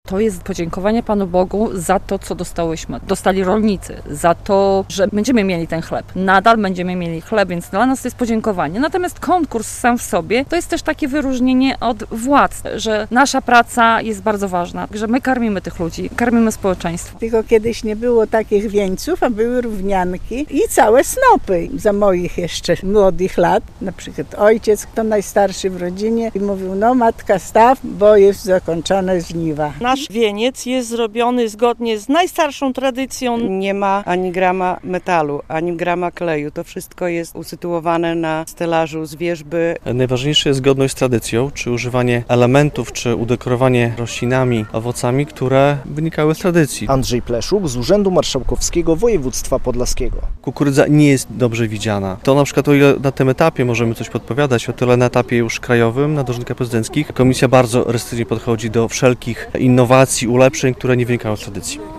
Konkurs na najpiękniejszy wieniec dożynkowy - relacja